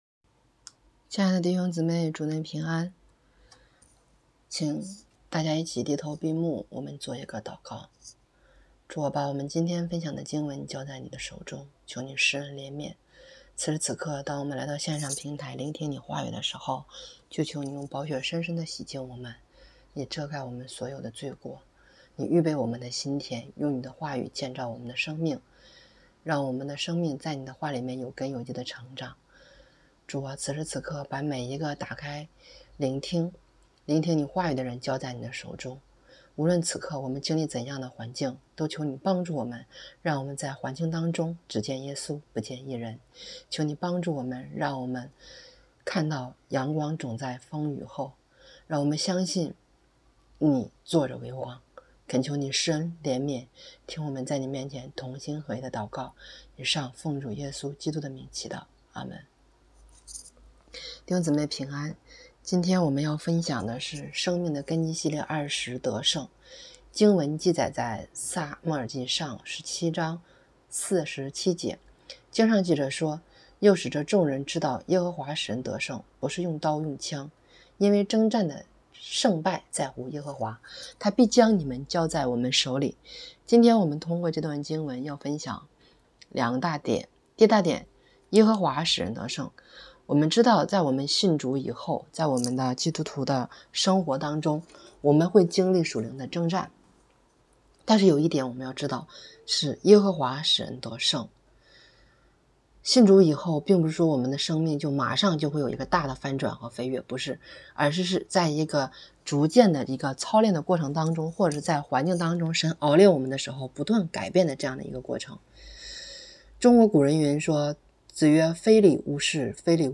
生命的根基系列20——得胜 | 北京基督教会海淀堂